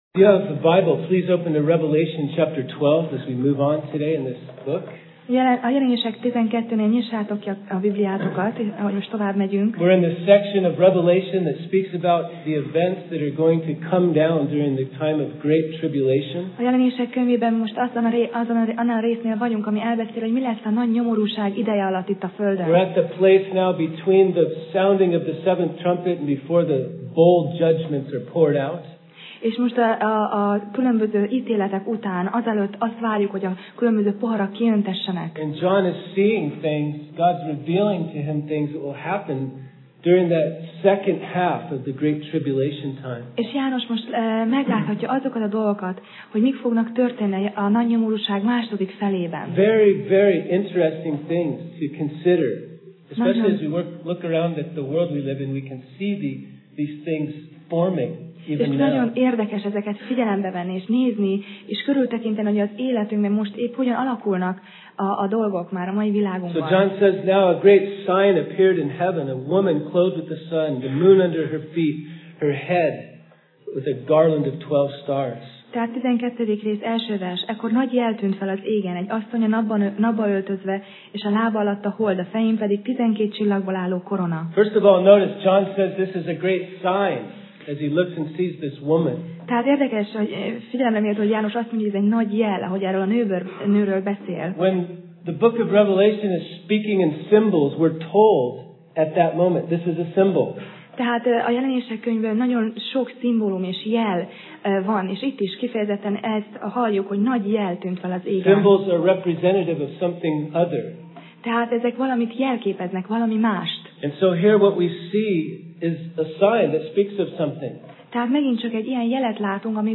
Passage: Jelenések (Revelation) 12:1-11 Alkalom: Vasárnap Reggel